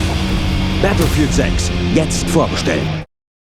sehr variabel, dunkel, sonor, souverän
Mittel minus (25-45)
Commercial (Werbung)